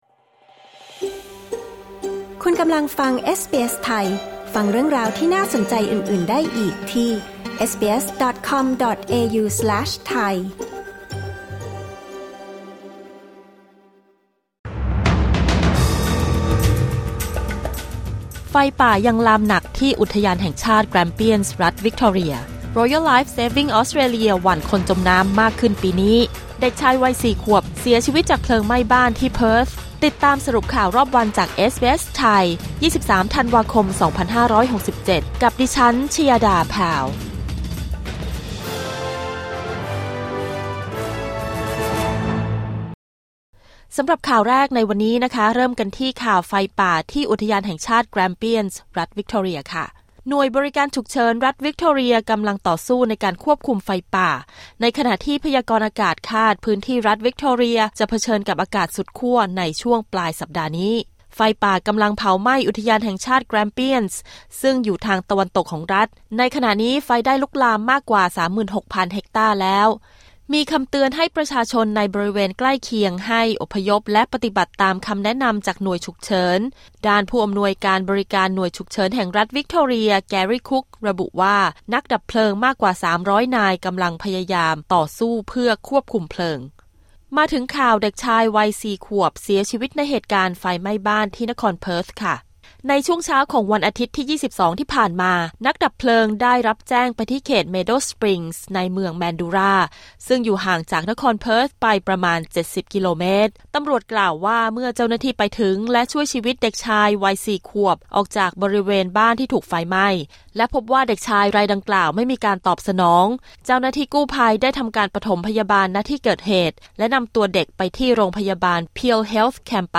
สรุปข่าวรอบวัน 23 ธันวาคม 2567